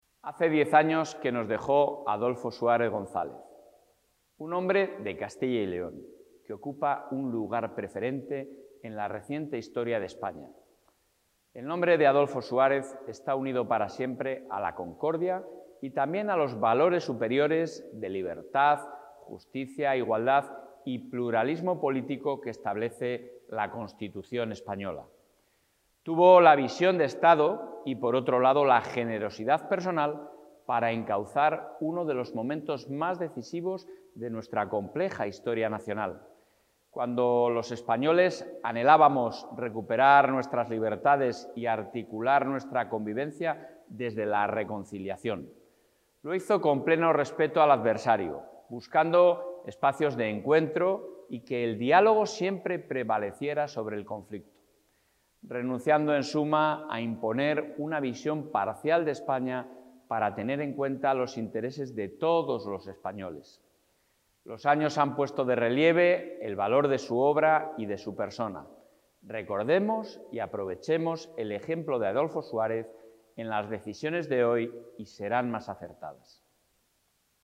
Mensaje del presidente.